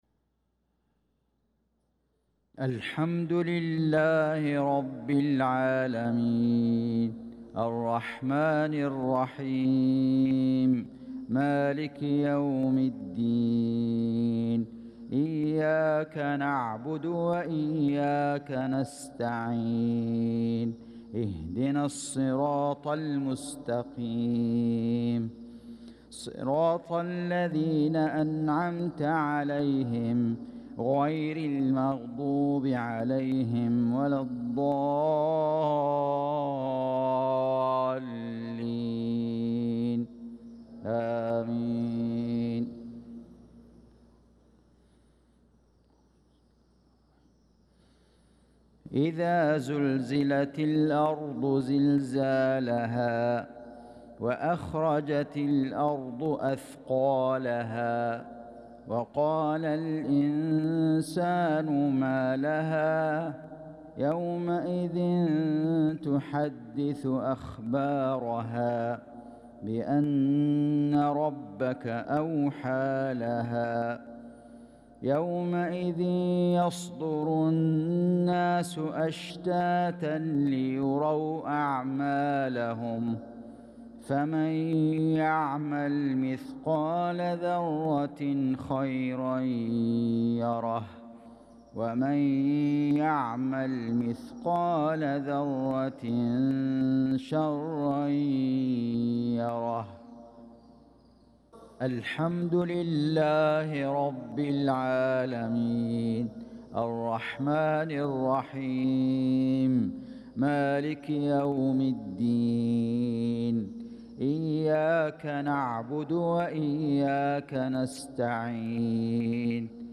صلاة المغرب للقارئ فيصل غزاوي 24 ذو القعدة 1445 هـ
تِلَاوَات الْحَرَمَيْن .